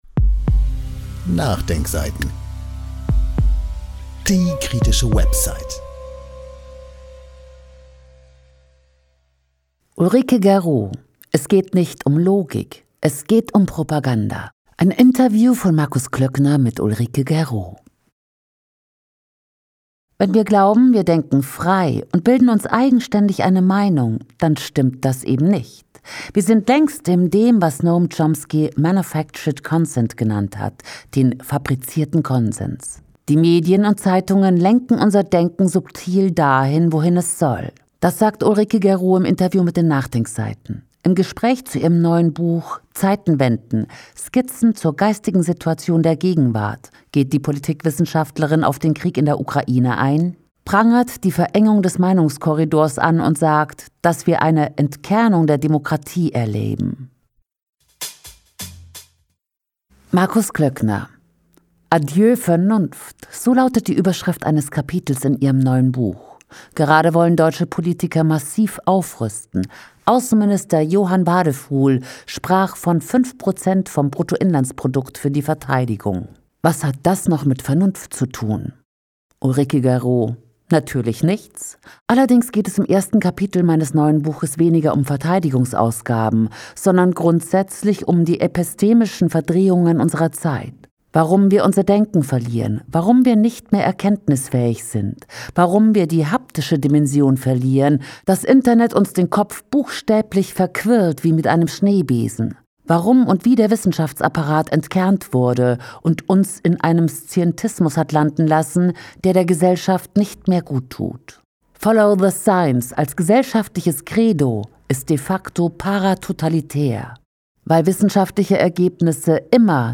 Das sagt Ulrike Guérot im Interview mit den NachDenkSeiten.